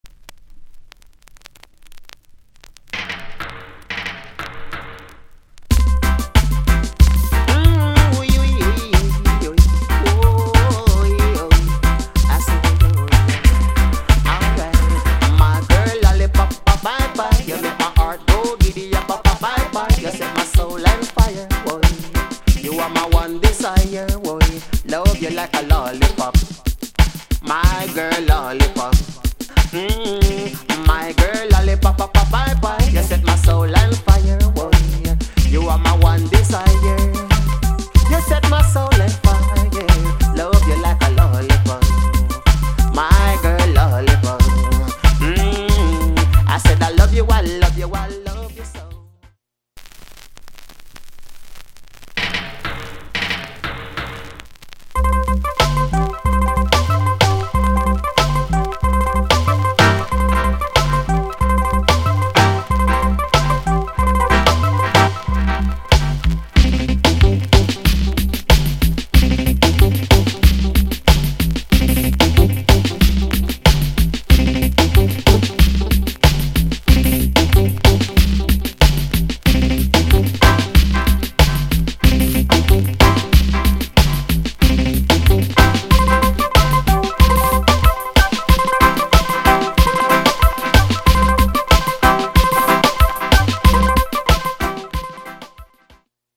変にアホ明るいところが面白い!!置換テキスト